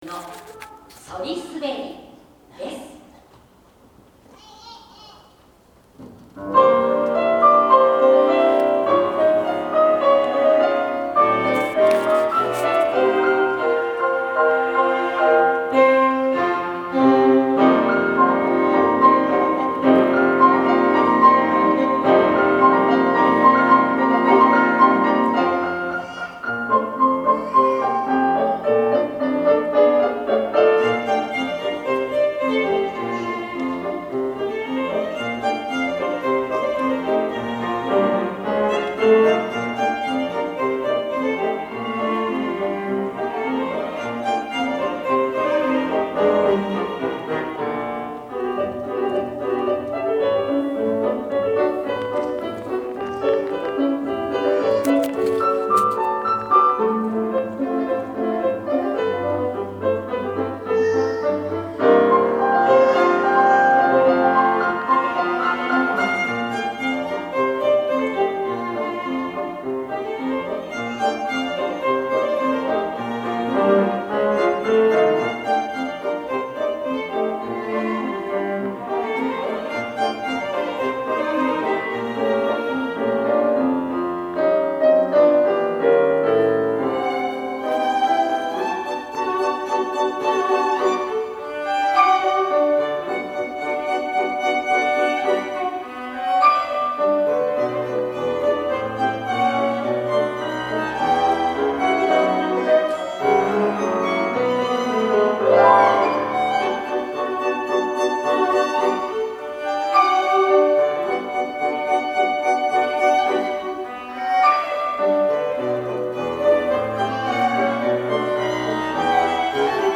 peter-music.mp3